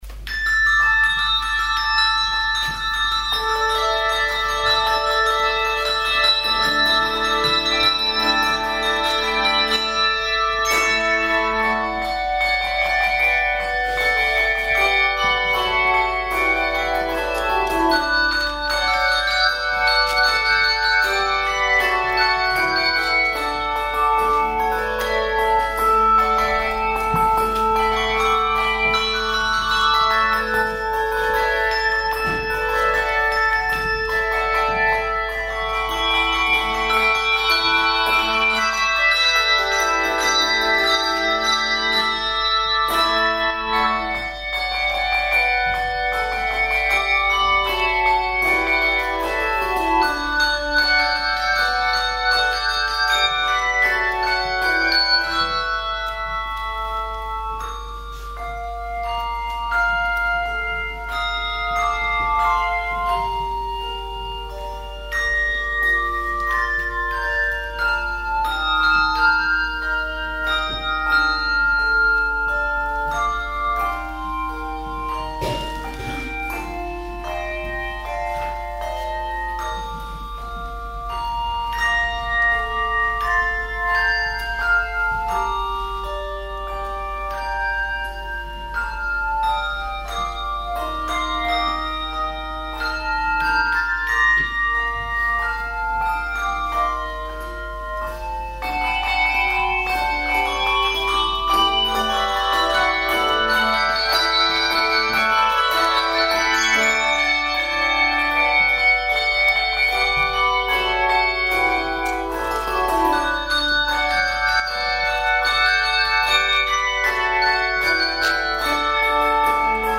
Bei den Hörbeispielen handelt es sich um Live - Mitschnitte, die mit einfacher Technik aufgenommen wurden. Nebengeräusche waren deshalb nicht zu vermeiden.